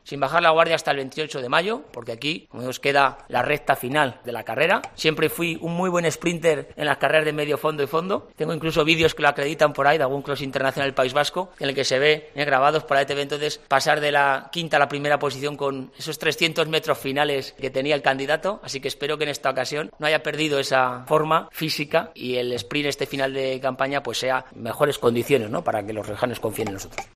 Y cerraba la rueda de prensa, echando mano, otra vez, de la metáfora de una carrera, reivindicándose como "un muy buen sprinter".